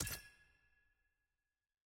sfx_ui_map_panel_confirm.ogg